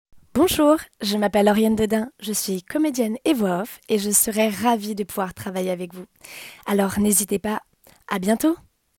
Bandes-son
Presentation
- Mezzo-soprano